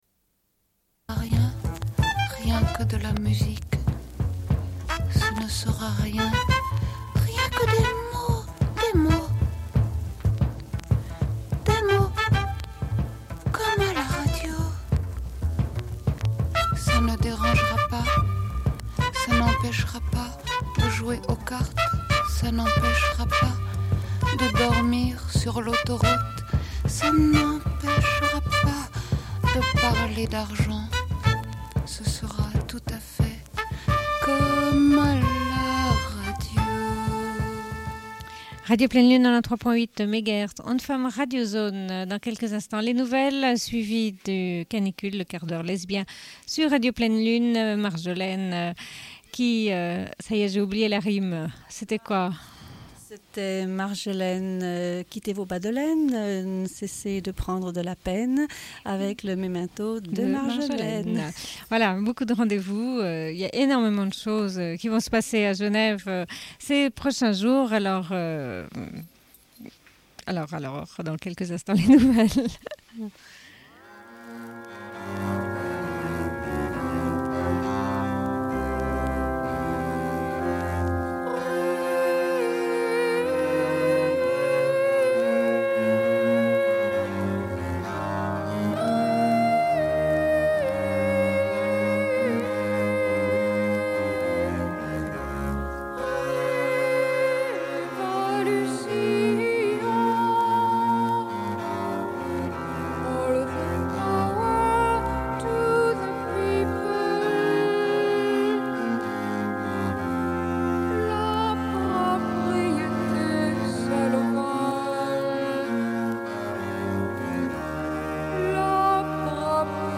Bulletin d'information de Radio Pleine Lune du 17.06.1992 - Archives contestataires
Une cassette audio, face B00:29:04